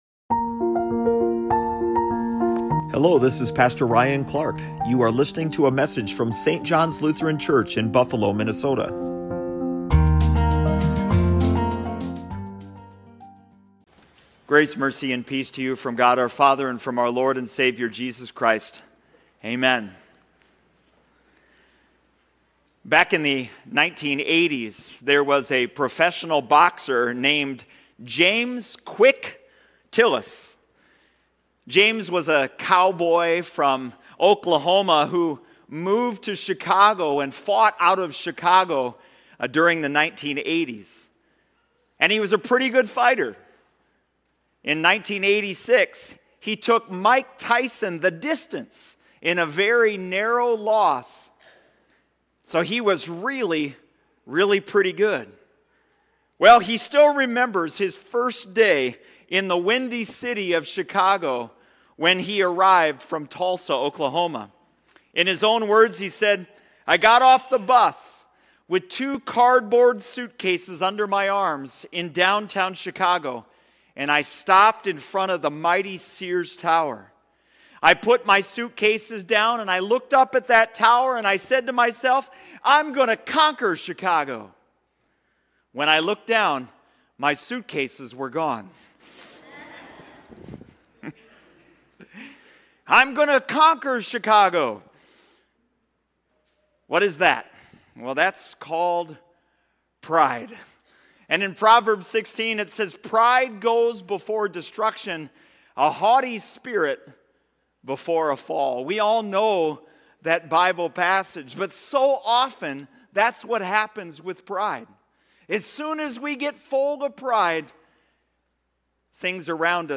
SERMON HOME Do you or someone you know think you've got life under control?